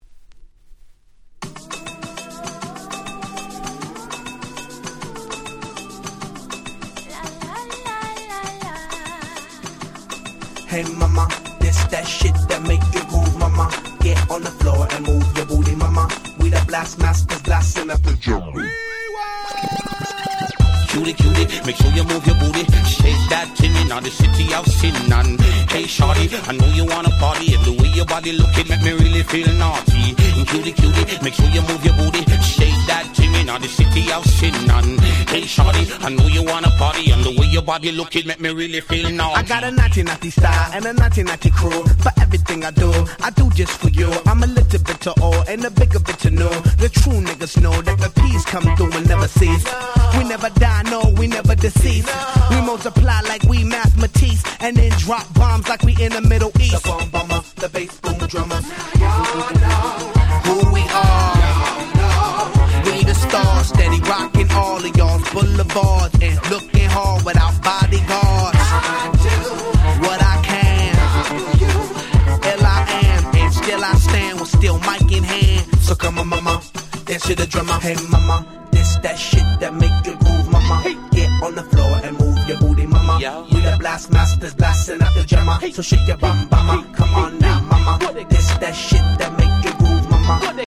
03' Super Hit Hip Hop / R&B !!